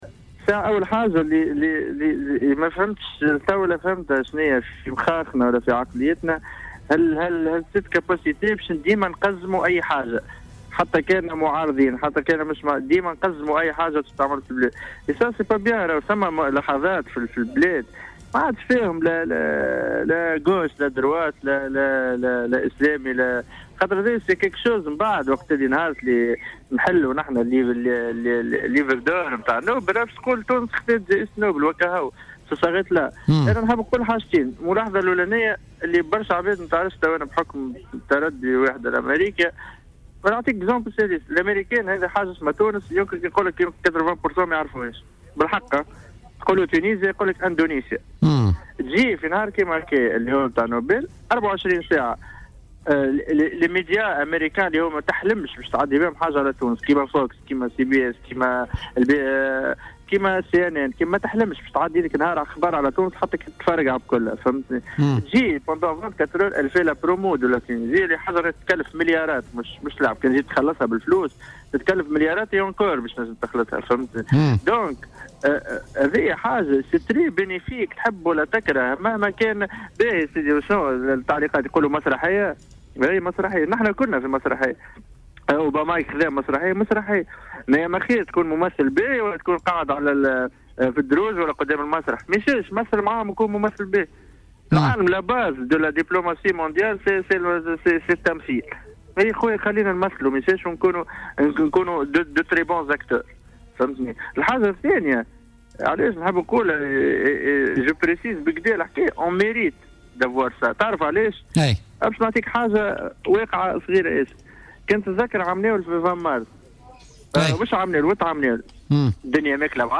قال المغني بيرم الكيلاني المعروف بـ"بندرمان" اليوم في مداخلة له في برنامج "بوليتيكا" إن فوز الرباعي الراعي للحوار الوطني بجائزة نوبل للسلام سيساعد كثيرا في الترويج لصورة تونس والتعريف بها في الخارج.